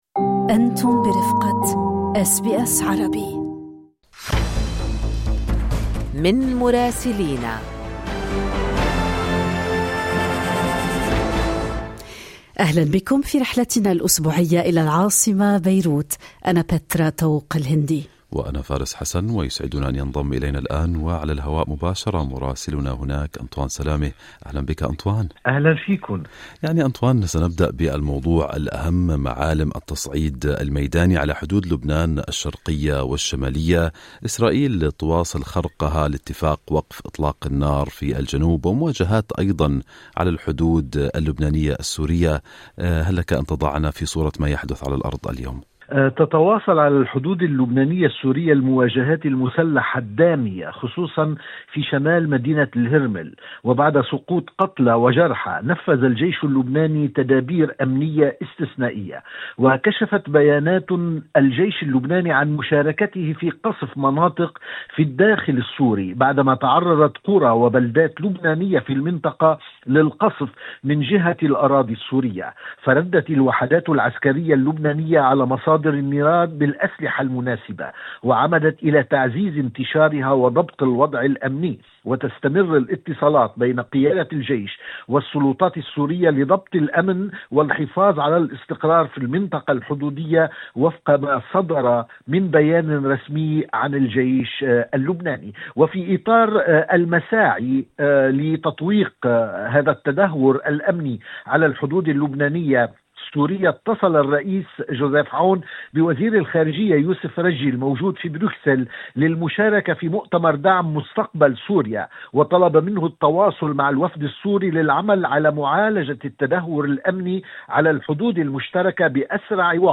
أعلنت وزارة الصحة اللبنانية أن غارة إسرائيلية في جنوب لبنان أسفرت عن مقتل شخصين ي، بعد أن أفادت القوات العسكرية الإسرائيلية بأنها استهدفت مقاتلين من حزب الله في المنطقة. التفاصيل مع مراسلنا في بيروت